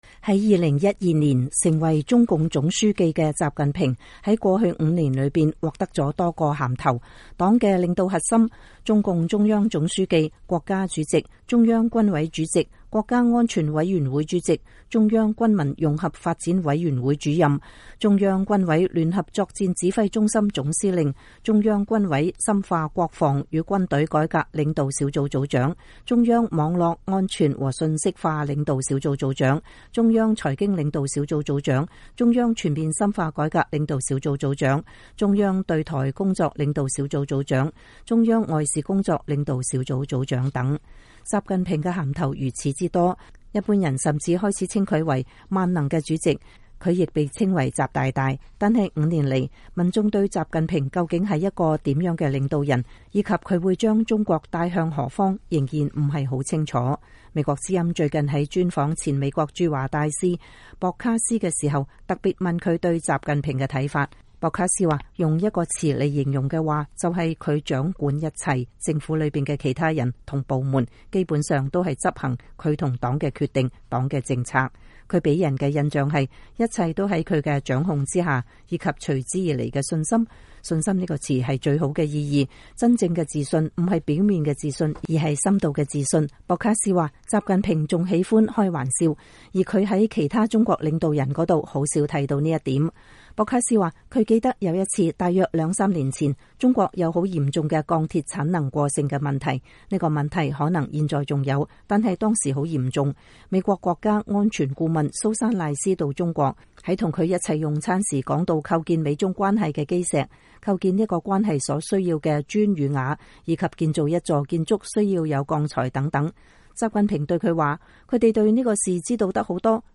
離任後已經四次去中國的博卡斯大使日前在華盛頓接受了美國之音的專訪，談到了他眼中的習近平究竟是一個甚麼樣的人。
美國之音最近在專訪前美國駐華大使博卡斯時特別問到他對習近平的看法。